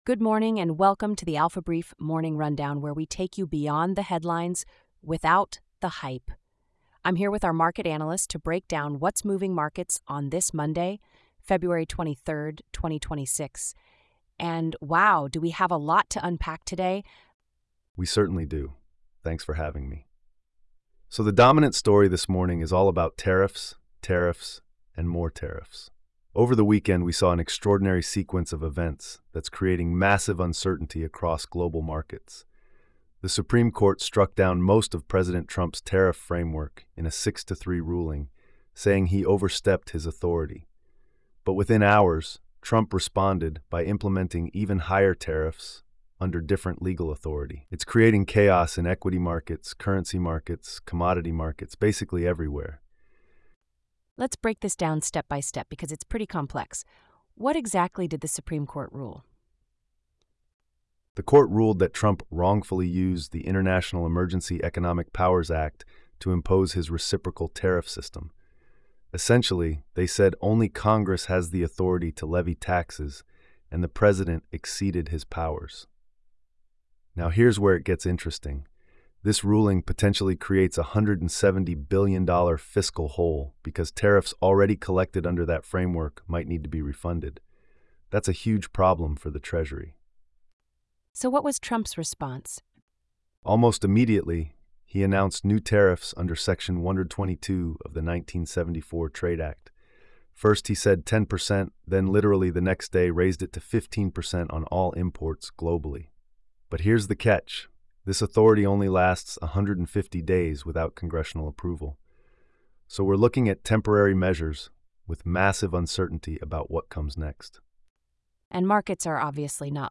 AI-generated market podcasts covering high-impact news you shouldn't miss.